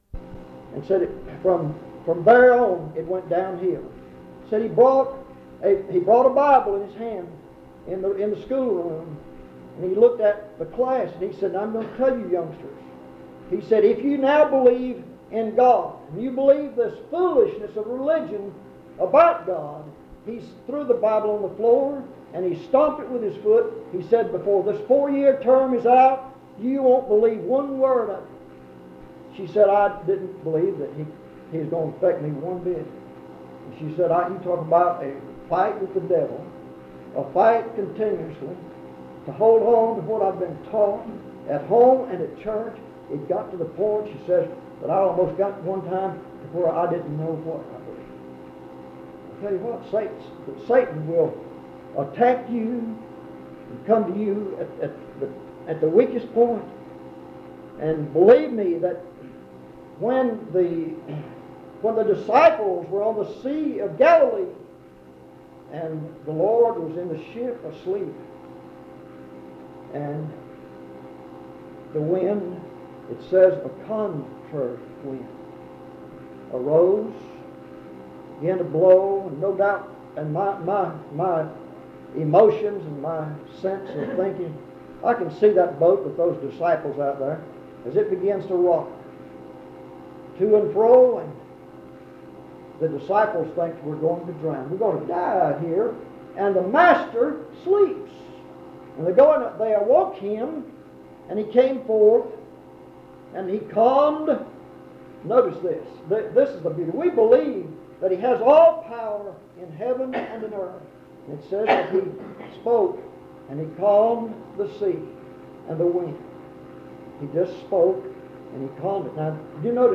Dans Collection: Monticello Primitive Baptist Church audio recordings La vignette Titre Date de téléchargement Visibilité actes PBHLA-ACC.002_004-B-01.wav 2026-02-12 Télécharger PBHLA-ACC.002_004-A-01.wav 2026-02-12 Télécharger